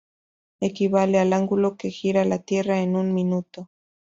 Hyphenated as mi‧nu‧to Pronounced as (IPA) /miˈnuto/